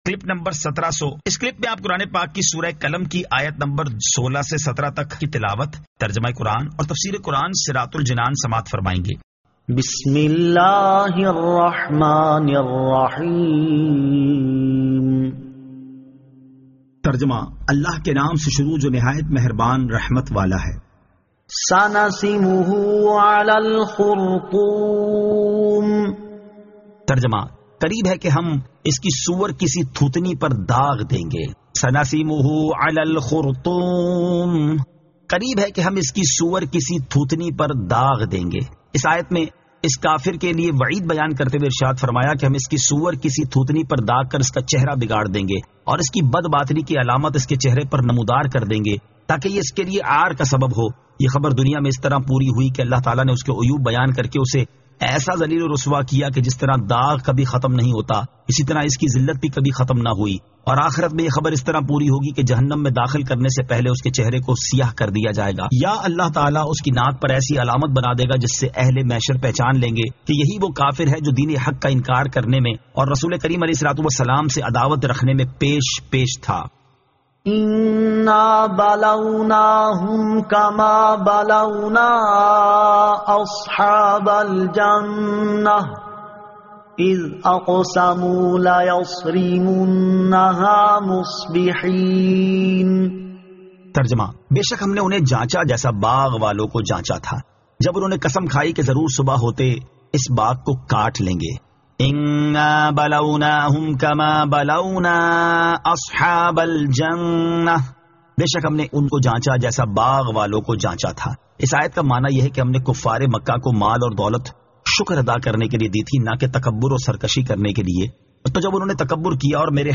Surah Al-Qalam 16 To 17 Tilawat , Tarjama , Tafseer
2024 MP3 MP4 MP4 Share سُوَّرۃُ القَلَمٗ آیت 16 تا 17 تلاوت ، ترجمہ ، تفسیر ۔